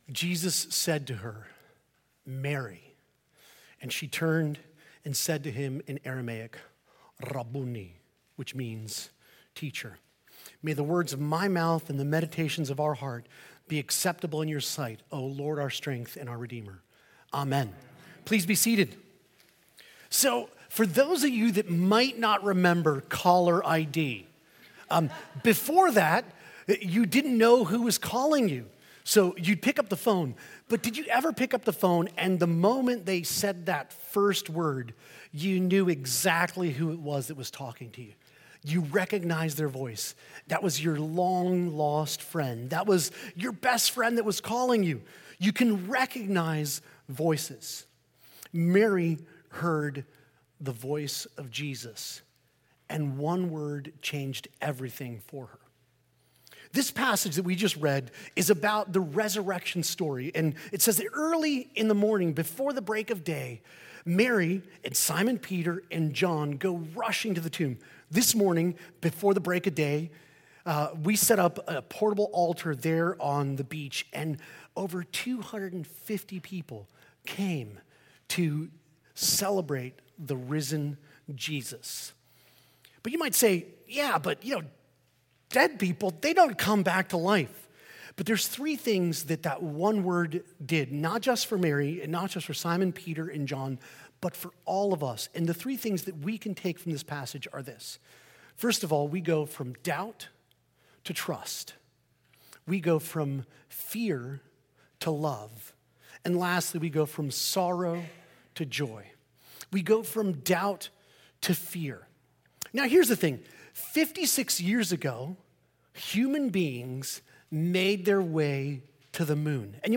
This Easter sermon centers on the resurrection story in John’s Gospel, especially the moment when the risen Jesus speaks Mary’s name— one word that changes everything .